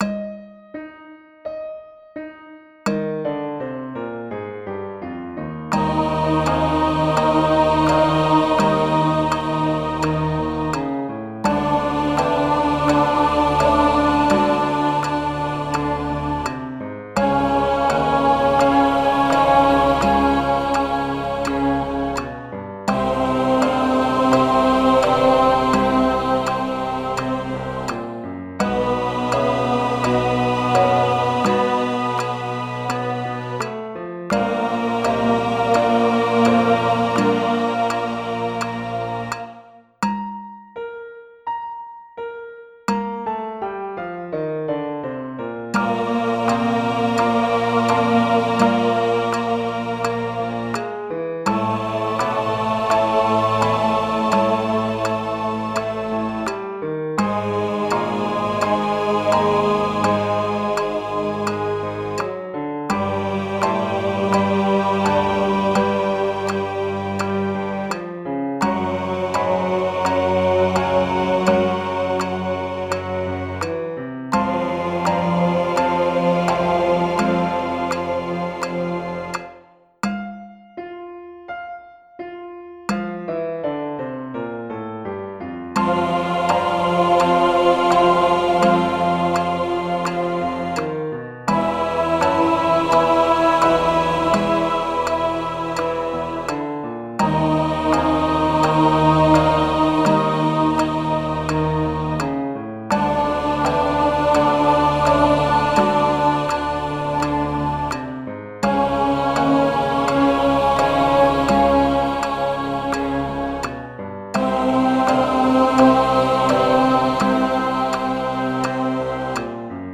First Habits Warm-Up – (tenor trombone)
Tempo = 84